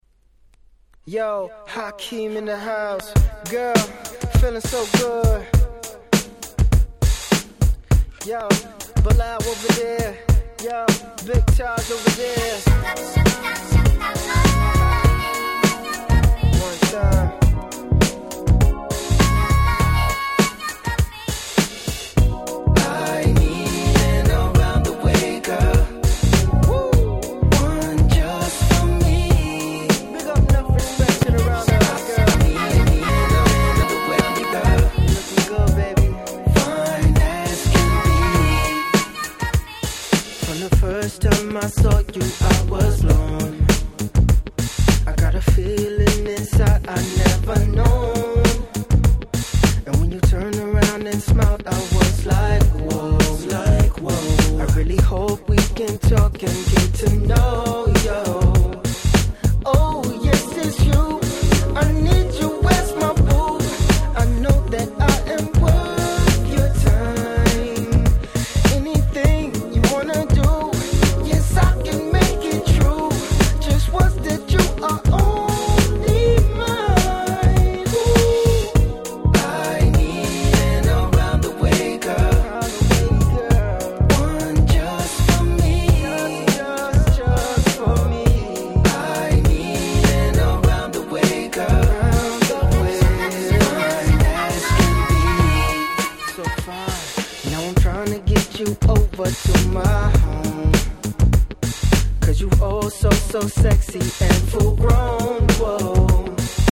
07' Nice Cover R&B !!